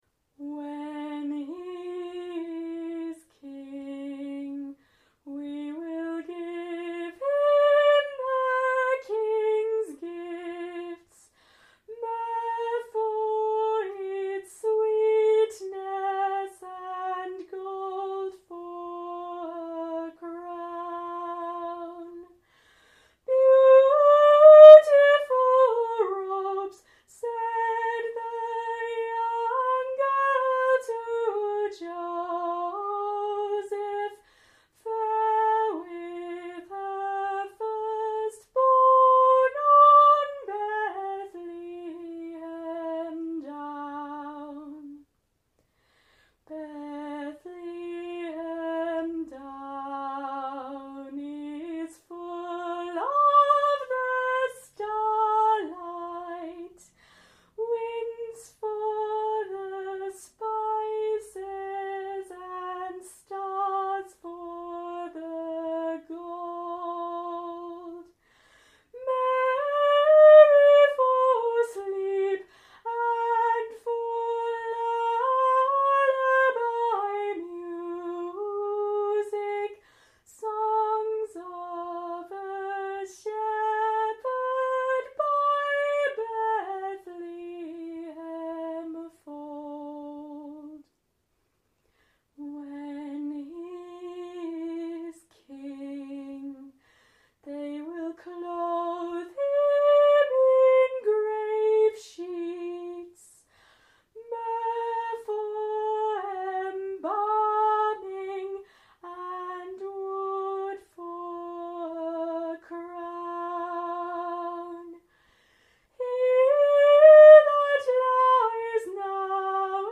Unaccompanied version of this beautiful piece.